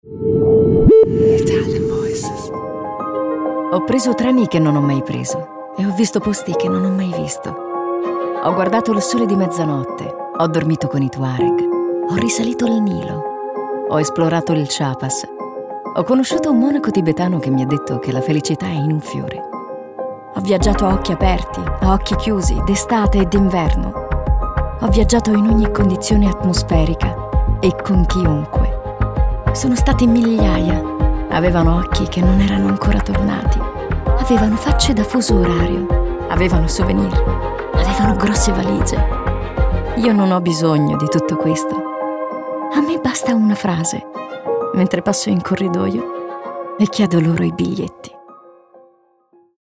Female Voices – Gigasound
Naked Reel